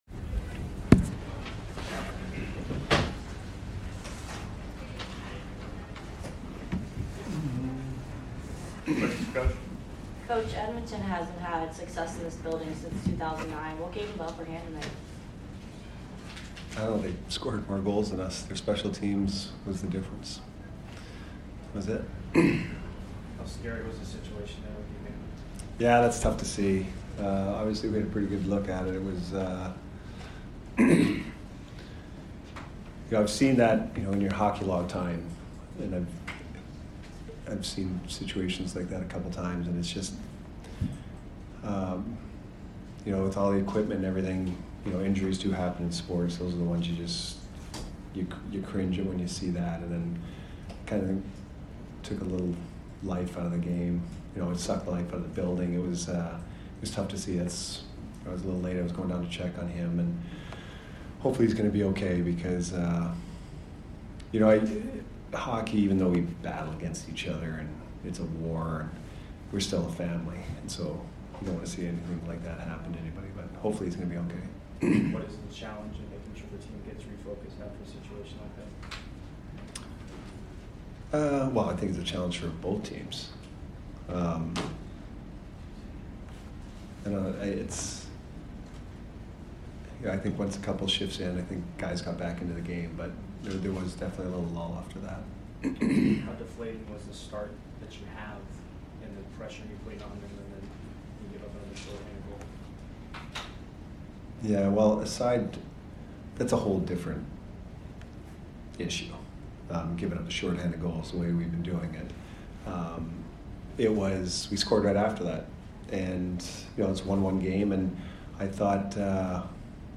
Head Coach Jon Cooper Post Game 11/8/22 vs EDM